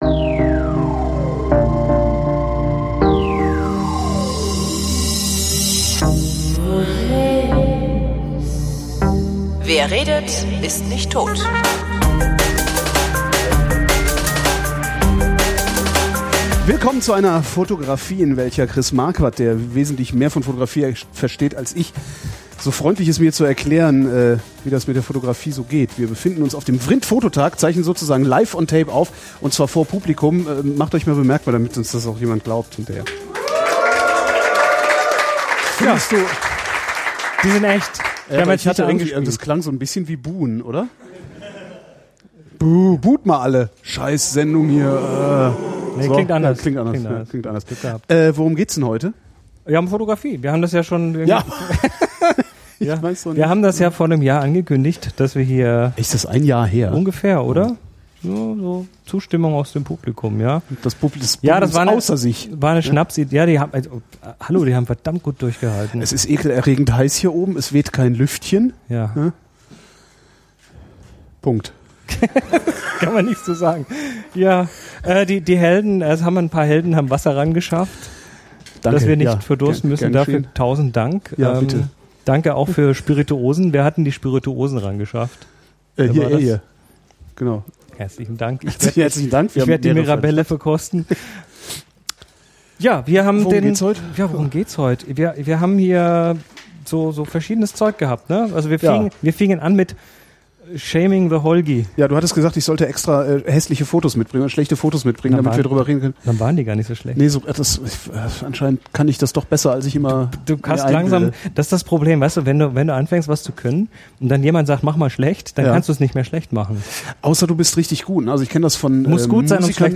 Hier ist jetzt endlich der Mitschnitt.